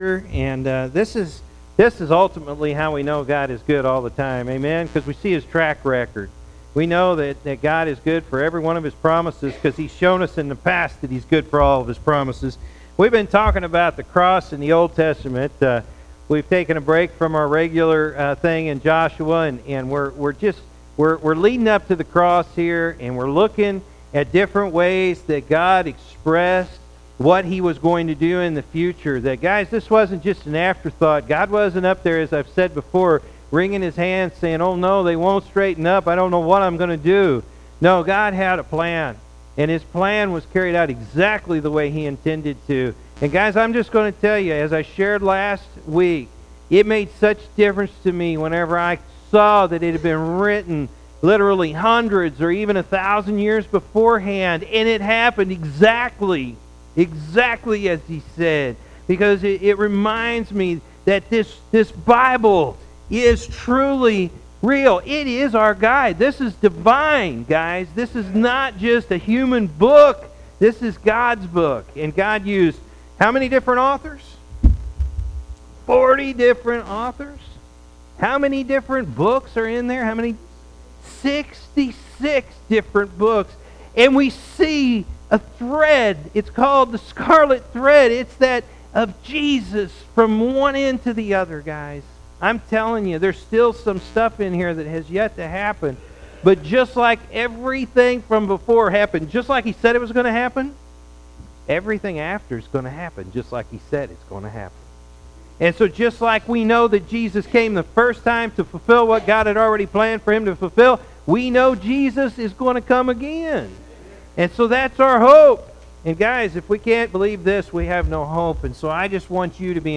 April-2-2017-morning-service.mp3